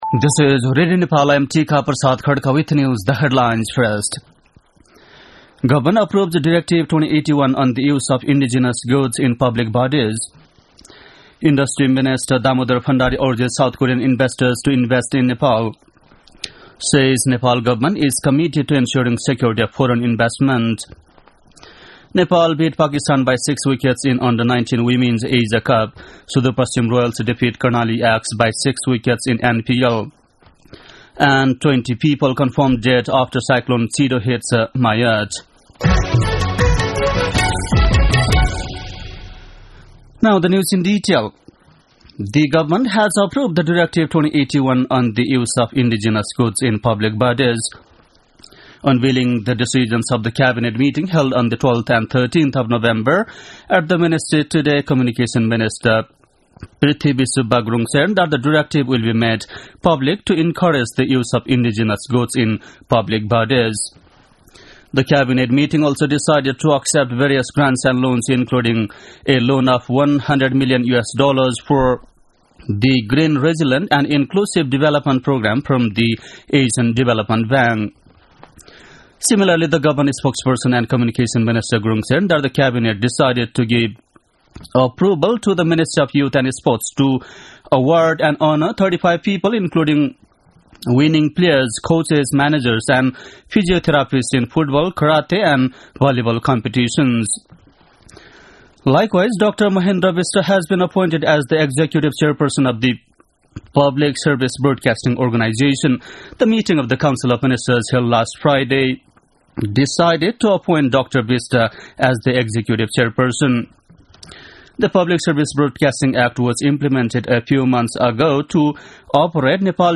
बेलुकी ८ बजेको अङ्ग्रेजी समाचार : २ पुष , २०८१
8-PM-English-News-9-1.mp3